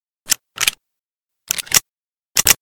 vz61_reload_empty.ogg